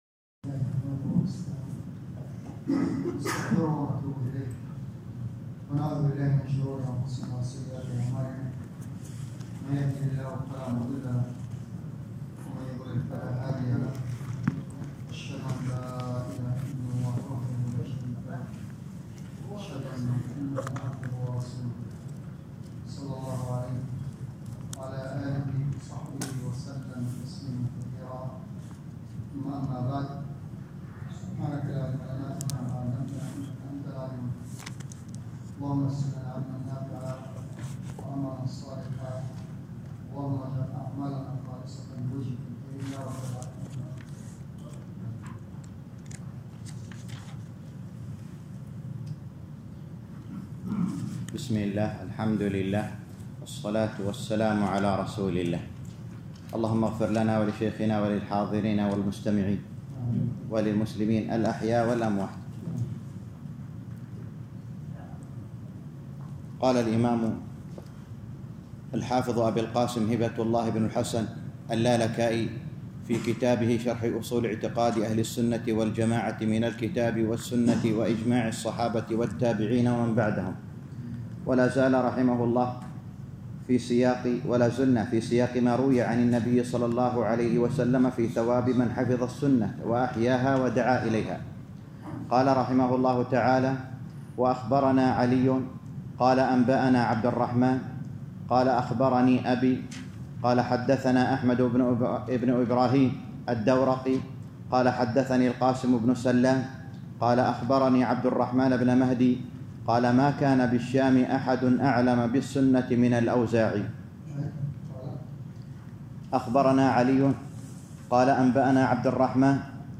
الدرس الثالث عشر - شرح أصول اعتقاد اهل السنة والجماعة الامام الحافظ اللالكائي _ 13